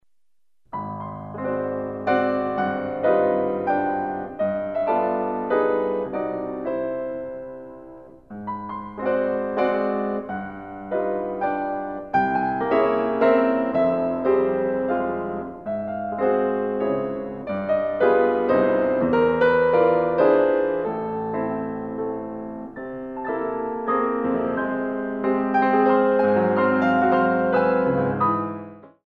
Traditional Compositions for Ballet Class
Performed on a Steinway
Stretch 3/4